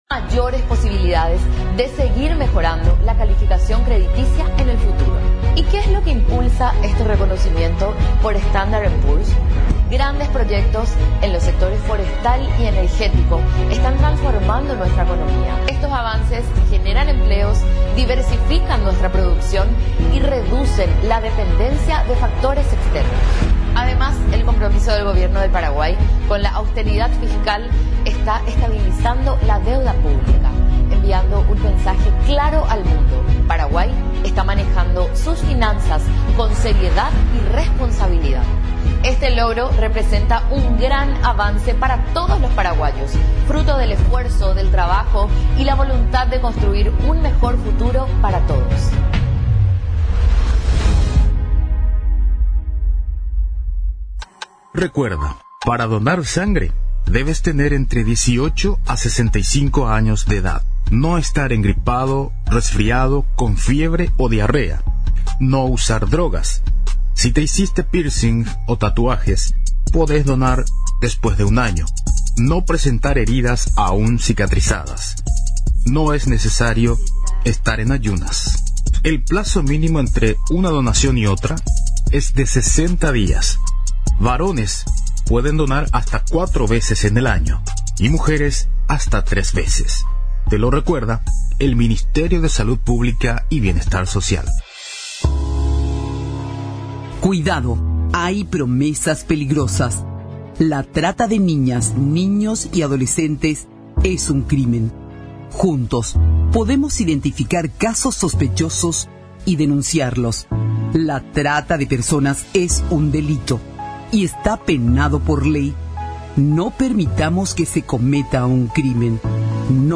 visitó este lunes los estudios de Radio Nacional del Paraguay